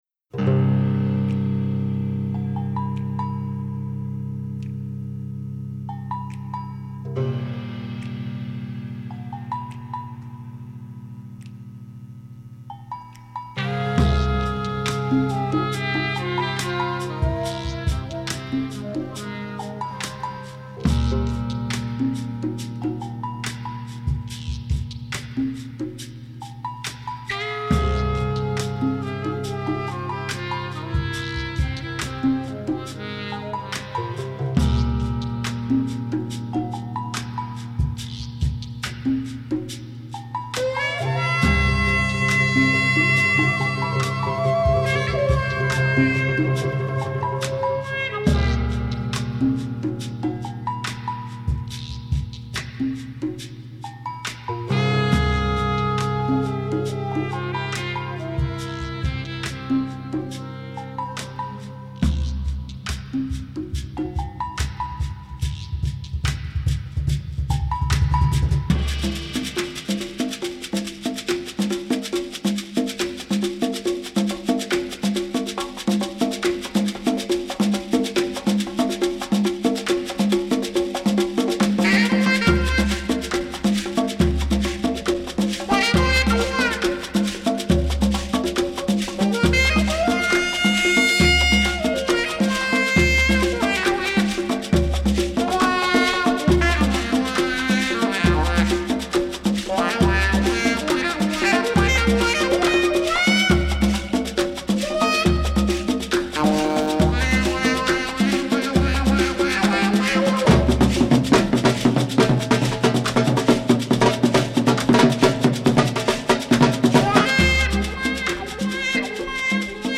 L'esprit amérindien appliqué au jazz